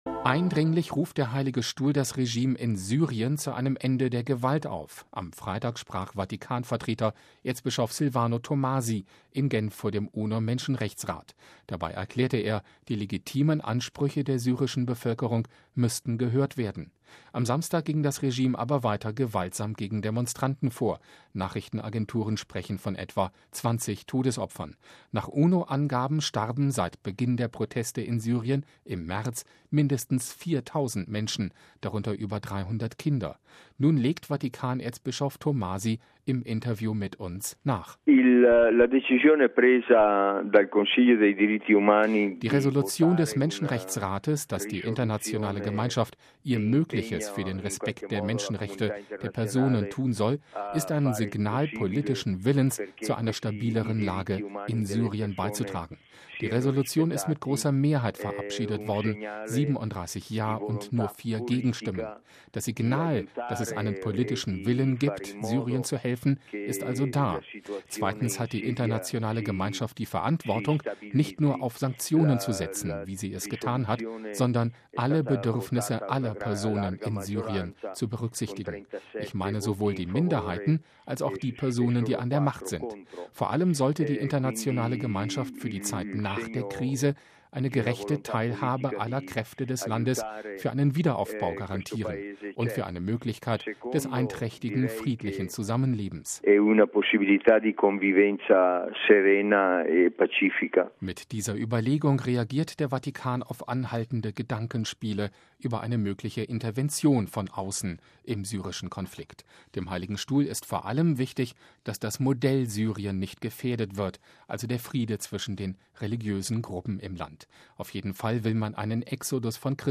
Nun legt Vatikan-Erzbischof Tomasi im Interview mit uns nach: